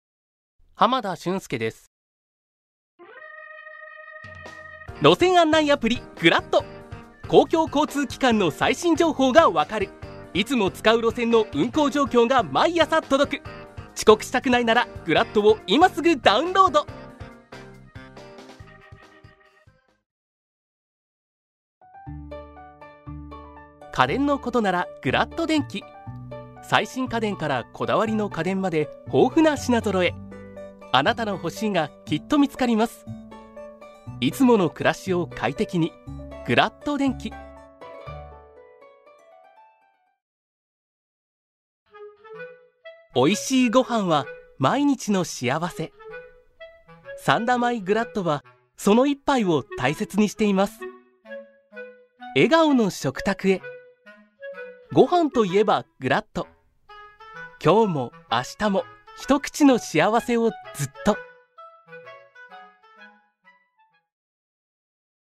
ボイスサンプル
• 飛び出す爽やかボイス
• 音域：高～中音
• 声の特徴：明るい、さわやか
• CM
真っ直ぐ元気な声のナレーションが特徴です。